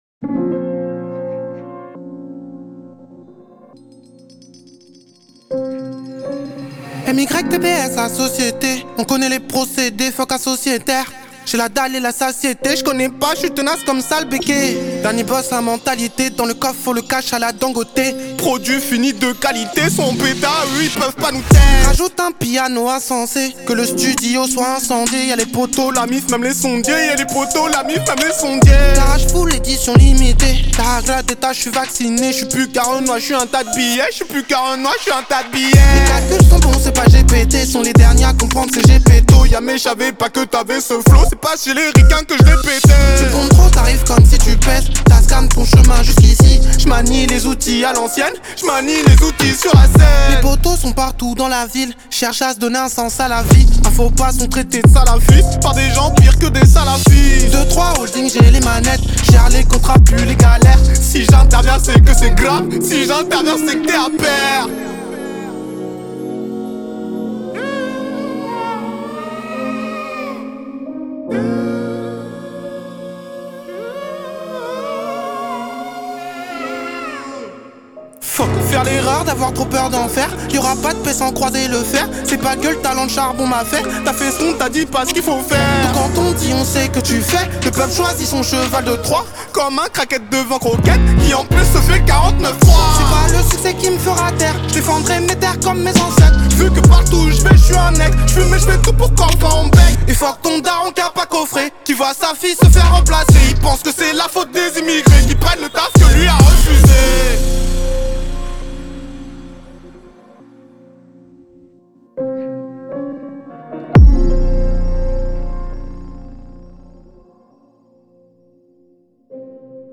Genero: Drill